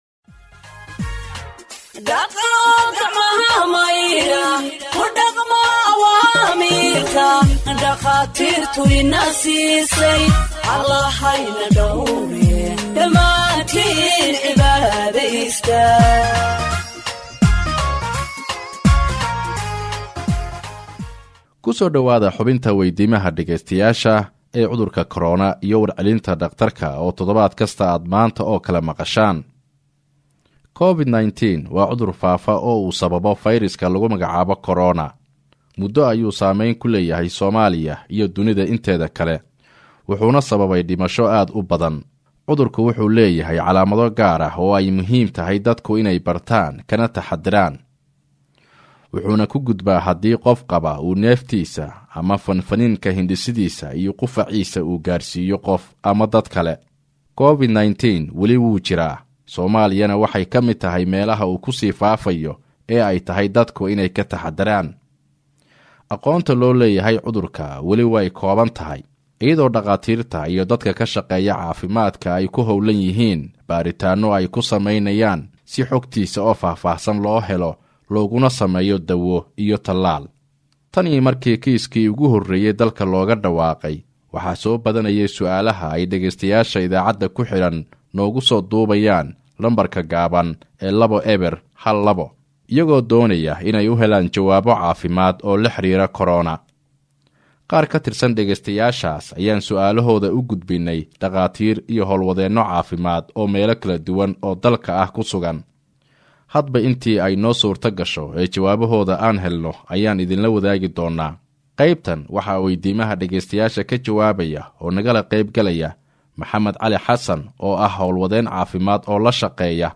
Health expert answers listeners’ questions on COVID 19 (41)
HEALTH-EXPERT-ANSWERS-LISTENERS-QUESTIONS-ON-COVID-19-41.mp3